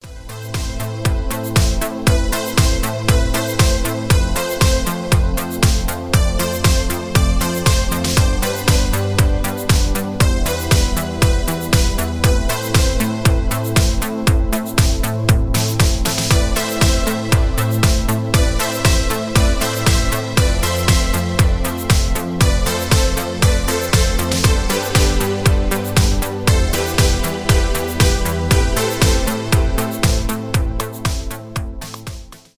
итало диско
ремиксы
танцевальные , без слов